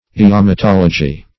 Search Result for " iamatology" : The Collaborative International Dictionary of English v.0.48: Iamatology \I*am`a*tol"o*gy\, n. [Gr.